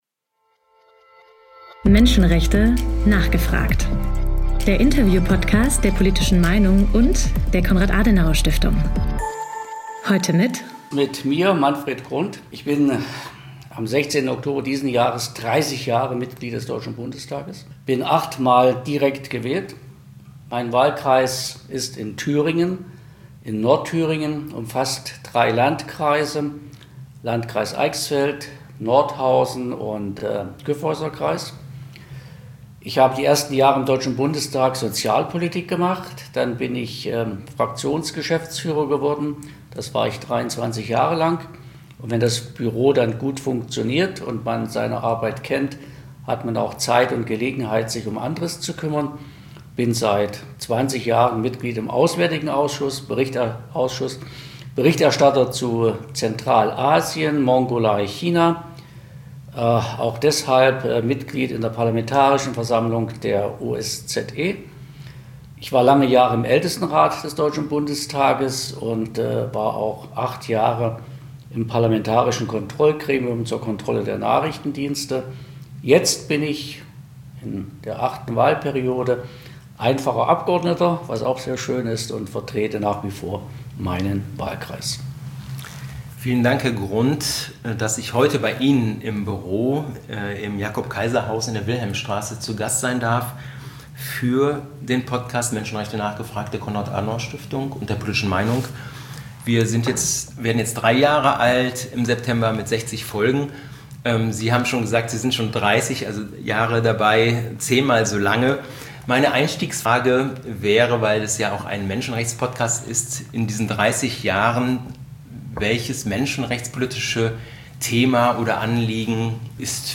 - Der Interview-Podcast rund ums Thema Menschenrechte